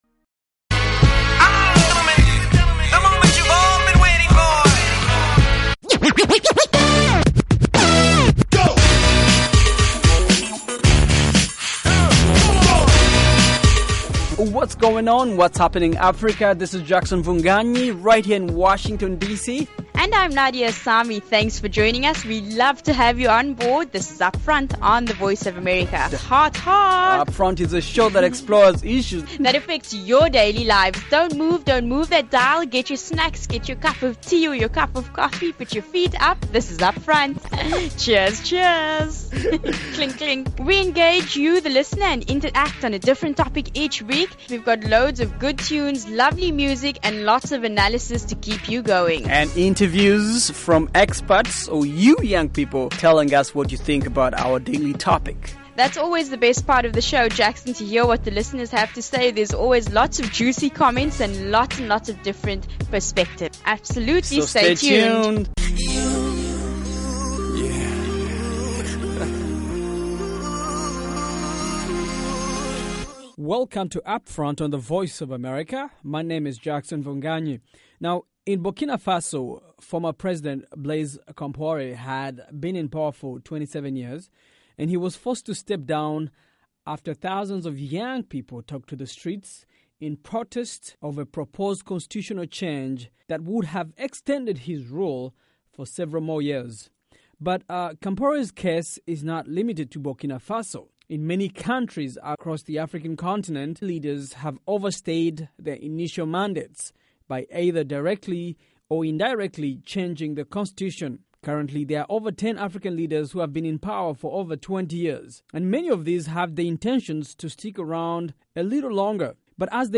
On this fresh, fast-paced show